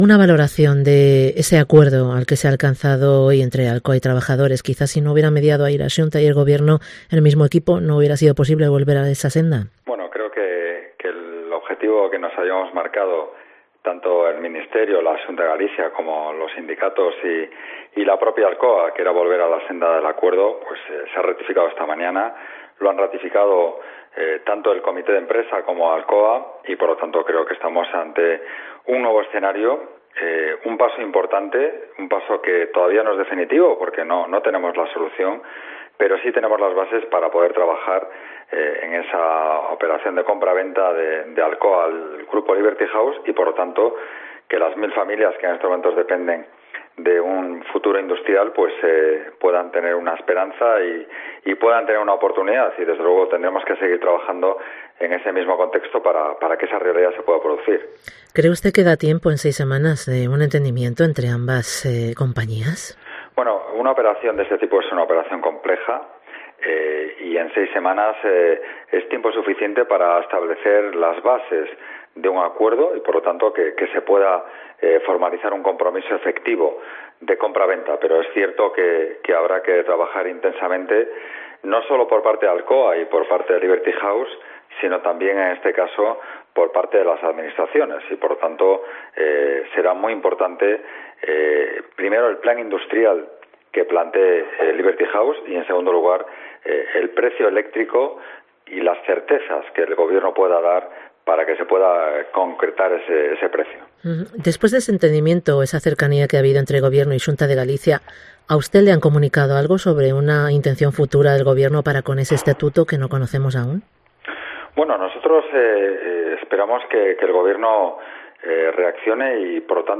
Entrevista con FRANCISCO CONDE, conselleiro de Industria de la Xunta de Galicia